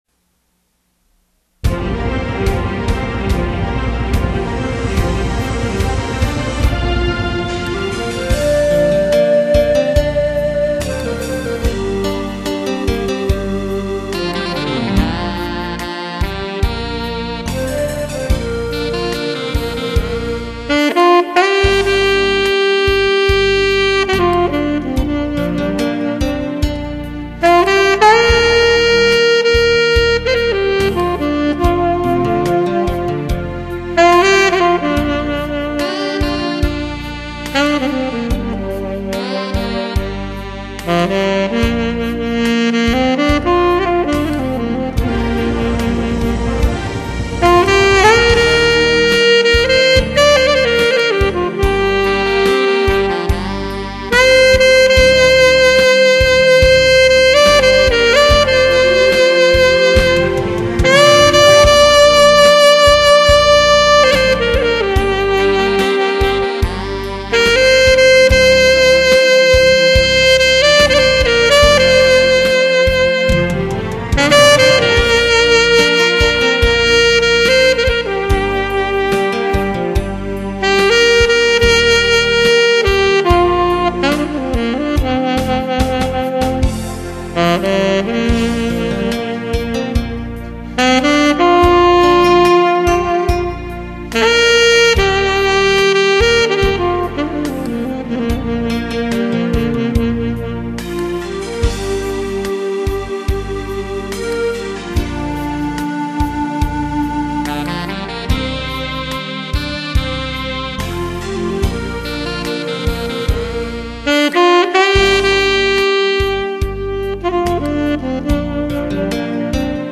대표 뽕짝...